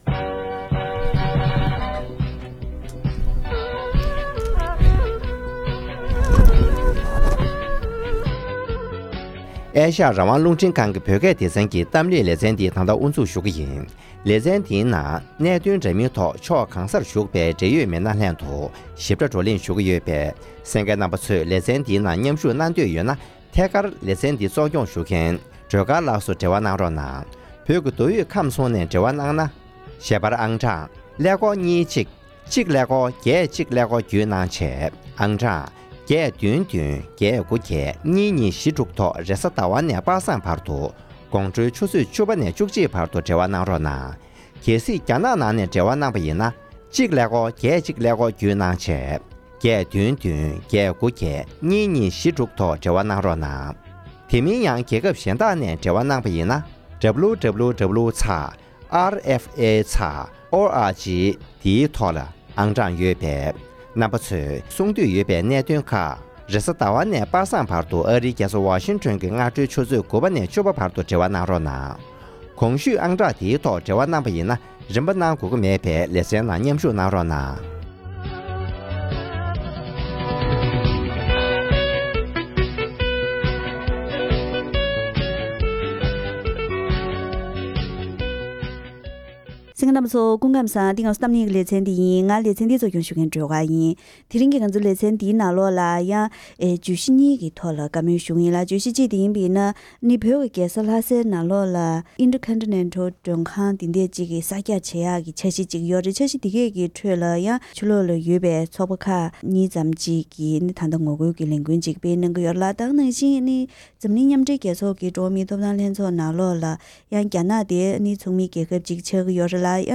༄༅། །དེ་རིང་གི་གཏམ་གླེང་ལེ་ཚན་ནང་། ལྷ་སར་མགྲོན་ཁང་གསར་རྒྱག་ལ་ངོ་རྒོལ་དང་། མཉམ་སྦྲེལ་རྒྱལ་ཚོགས་ཀྱི་འགྲོ་བ་མིའི་ཐོབ་ཐང་ལྷན་ཚོགས་ནང་རྒྱ་ནག་མཉམ་ཞུགས་ལ་ངོ་རྒོལ་གྱི་ལས་འགུལ་སྤེལ་བ་བཅས་ཀྱི་གནད་དོན་གཉིས་གཞི་ལ་བཞག་ནས་བོད་ནང་གི་གནས་སྟངས་གླེང་བ་ཞིག་གསན་རོགས་གནང་།།